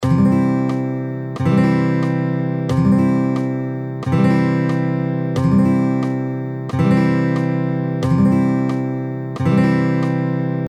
続いてEX５は2分音符のリズムになります。
※エクササイズの際のコードストロークは全てダウンストロークで演奏しましょう。
EX５　2分音符のリズムを弾いてみよう